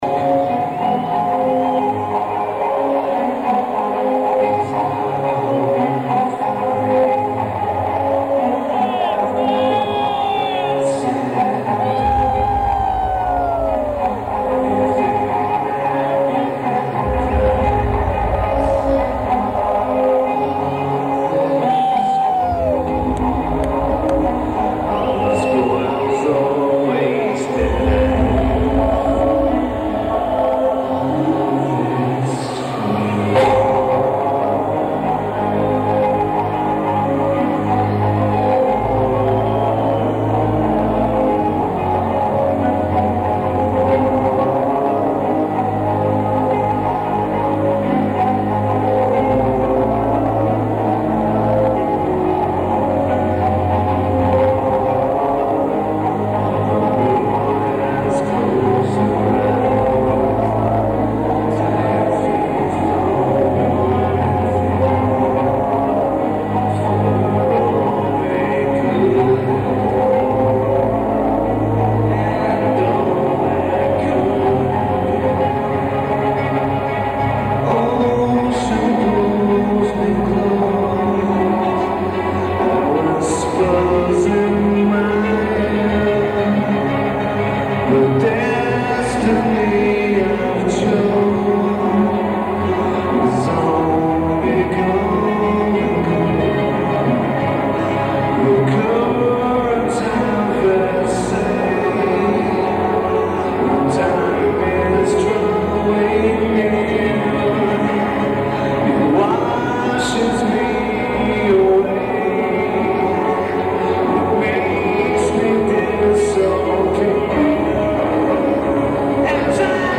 The Palace of Auburn Hills
Average recording.